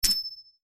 Звук из игры подобран алмаз